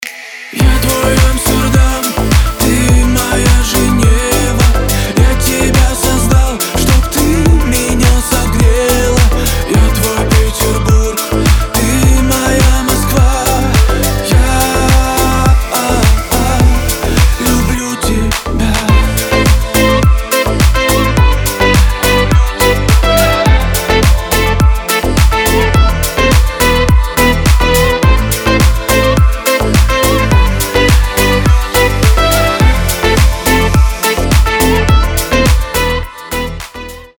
• Качество: 320, Stereo
поп
мужской вокал
deep house